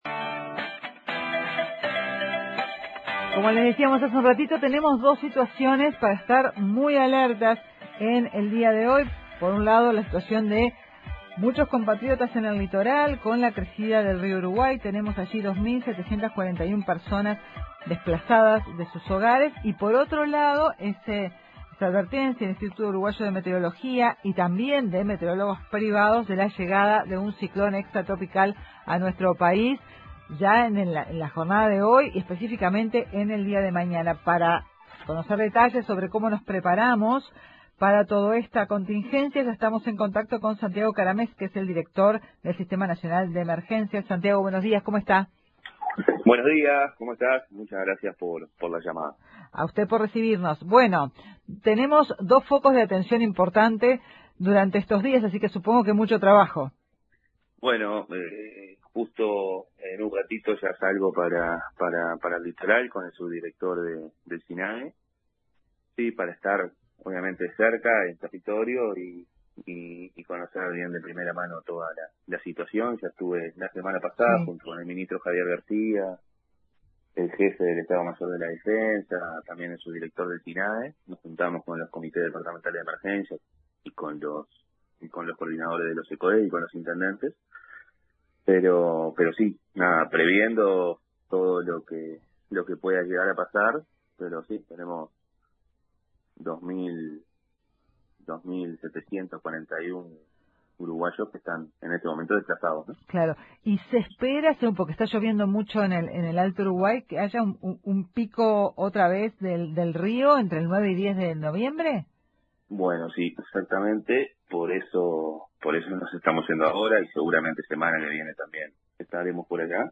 El director del Sinae, Santiago Caramés, dijo a Informativo Uruguay que según el monitoreo diario de la CTM de Salto Grande, estamos previendo un pico para la semana que viene, y esto nos pone en alerta porque va a significar más gente desplazada de sus hogares”.